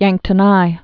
(yăngktə-nī)